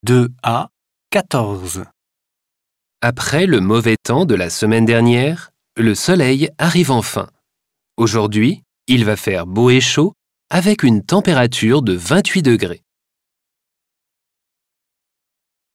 Folgende Hördialoge stehen zur Verfügung: